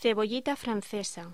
Locución: Cebollita francesa
voz